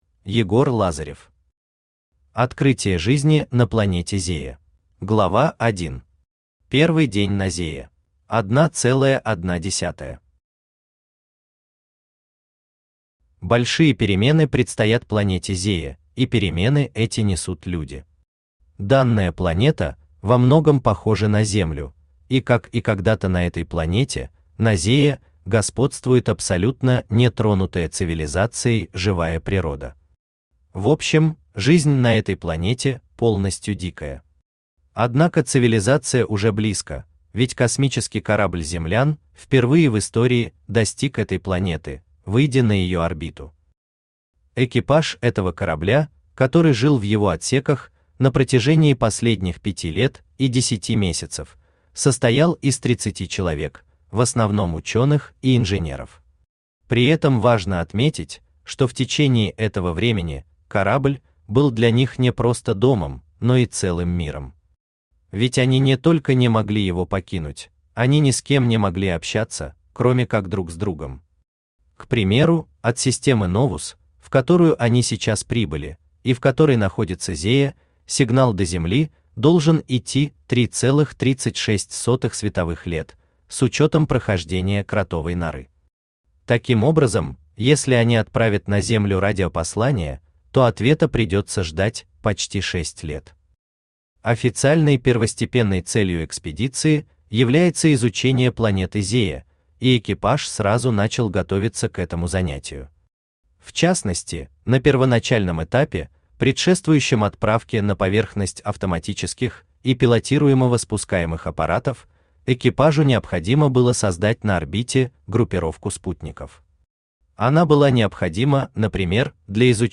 Аудиокнига Открытие жизни на планете Зея | Библиотека аудиокниг
Aудиокнига Открытие жизни на планете Зея Автор Егор Лазарев Читает аудиокнигу Авточтец ЛитРес.